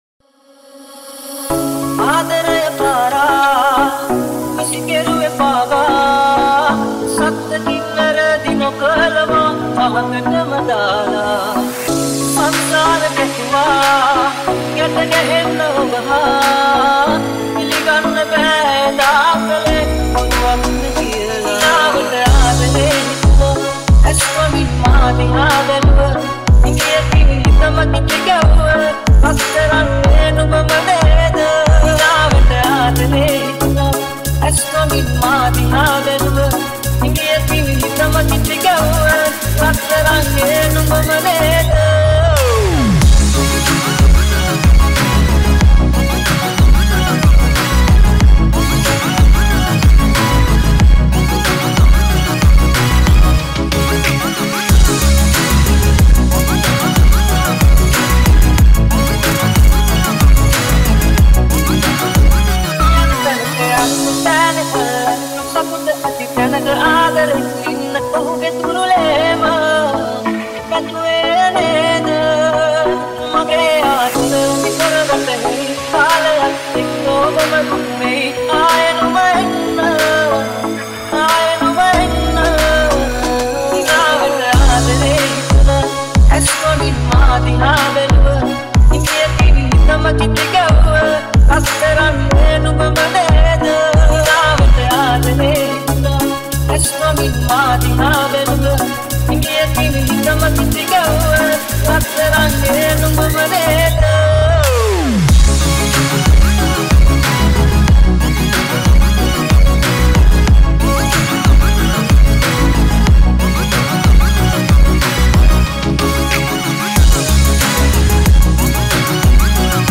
Remix Song | Dj Song